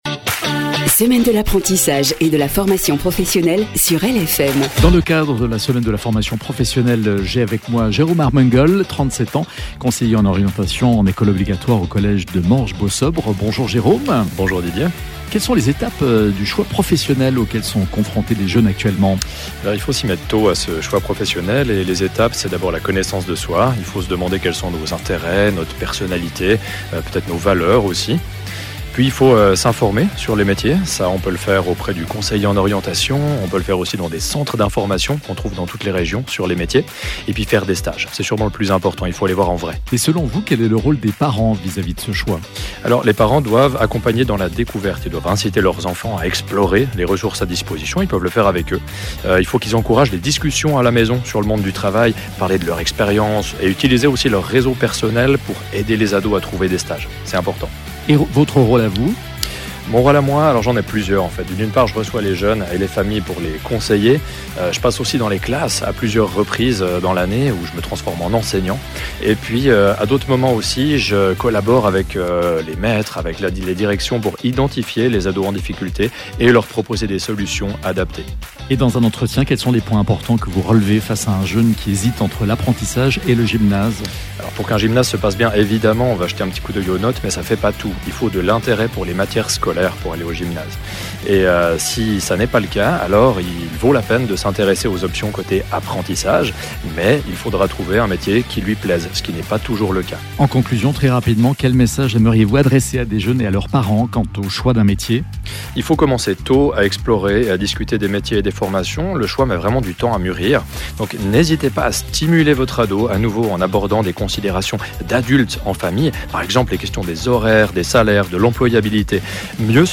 Dans le canton de Vaud, des invités parleront de leur expérience ou de leur vision du choix d’un apprentissage, chaque jour à 16h18 et à 18h48 sur LFM.
Programme des interviews